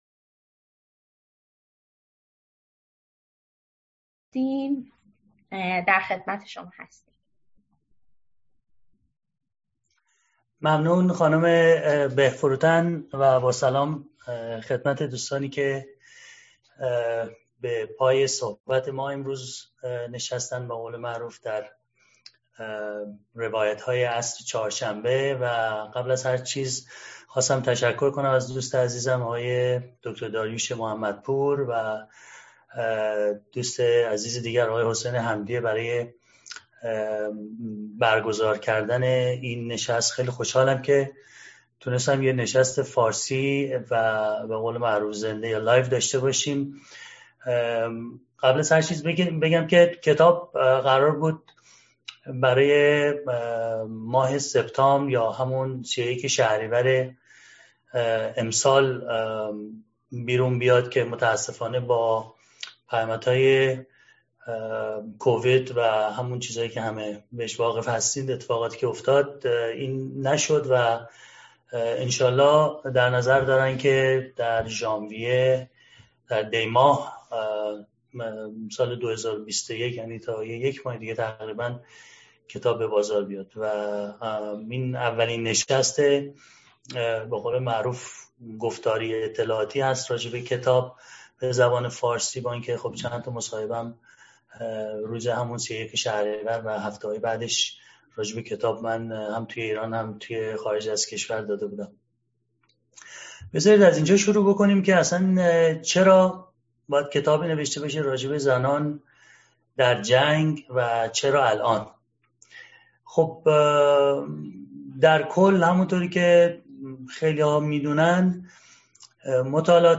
در ادامه، می‌توانید گفتگویی را با نویسندهٔ کتاب در مورد محتوای این کتاب بشنوید. این گفتگو توسط گردانندگان کانال تلگرامی «روایت‌های عصر چهارشنبه» صورت گرفته است.